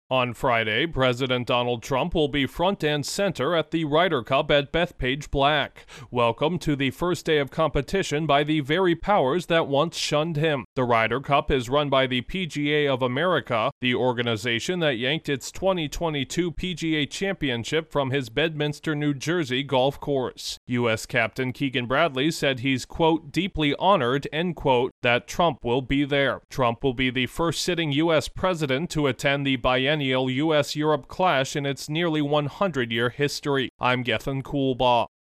Donald Trump is set to make history as the first sitting president to attend the Ryder Cup. Correspondent